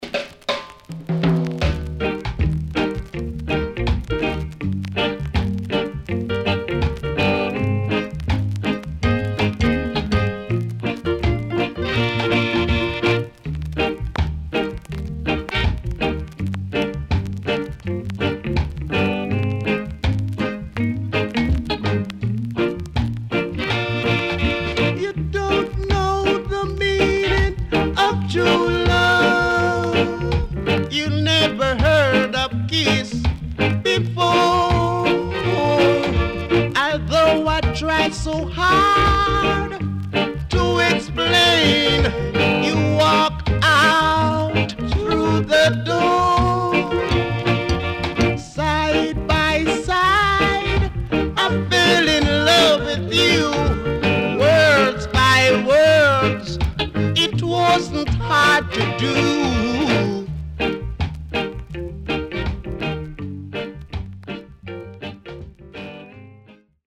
CONDITION SIDE A:VG(OK)
Rare.W-Side Good Rocksteady Vocal
SIDE A:所々チリノイズがあり、少しプチノイズ入ります。